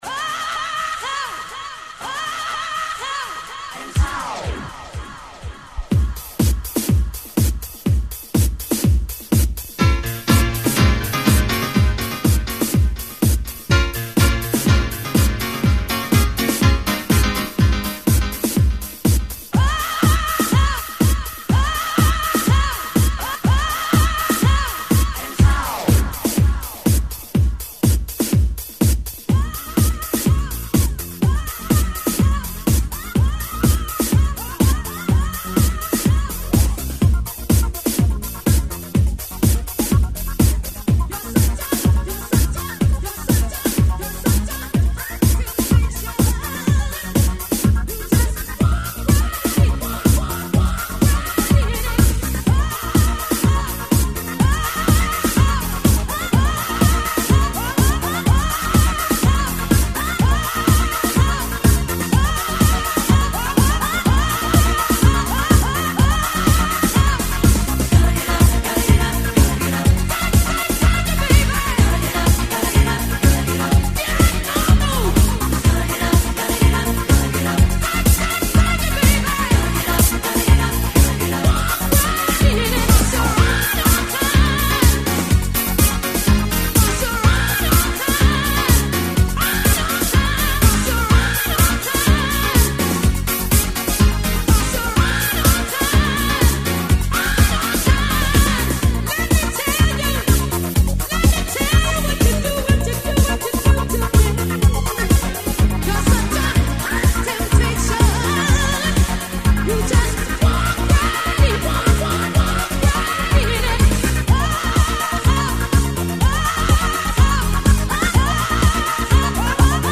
Жанр: Italo-Disco, Hi NRG, Euro House, Eurobeat
Формат - CD, Compilation, Mixed, Stereo